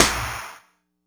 snare02.wav